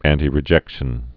(ăntē-rĭ-jĕkshən, ăntī-)